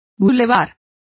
Complete with pronunciation of the translation of boulevards.